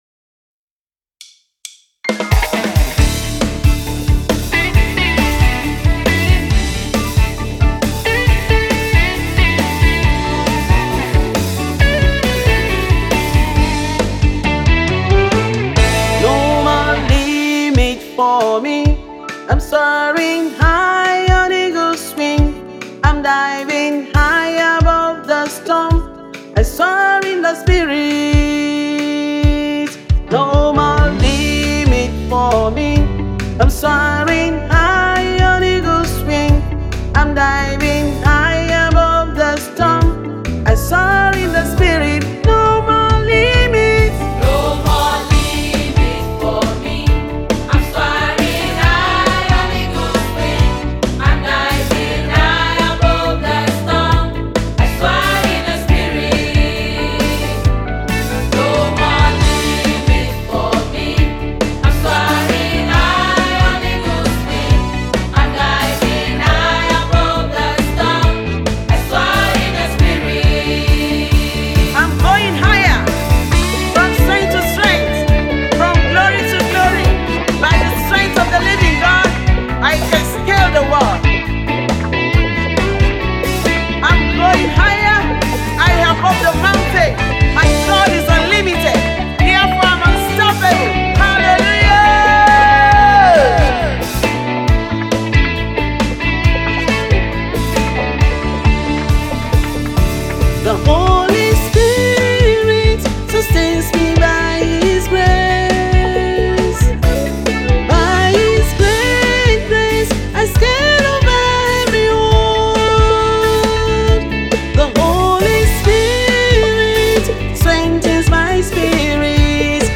an inspiring and encouraging song